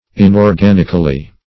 Inorganically \In`or*gan"ic*al*ly\, adv.